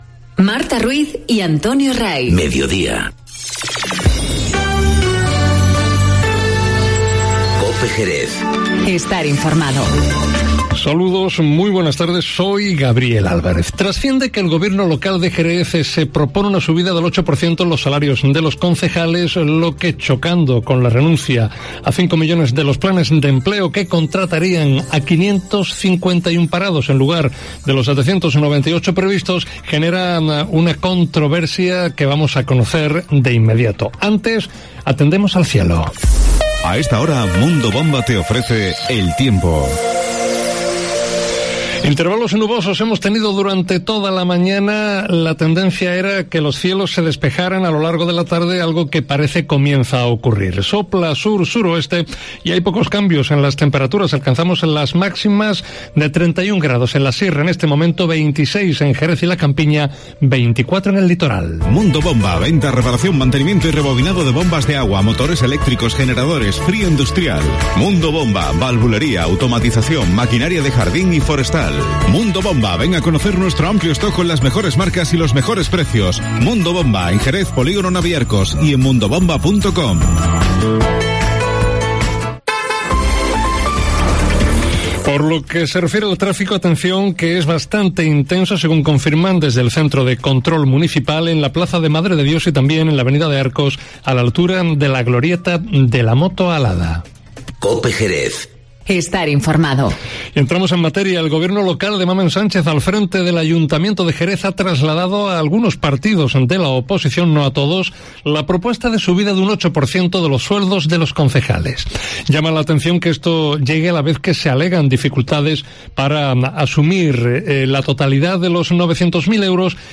Informativo Mediodía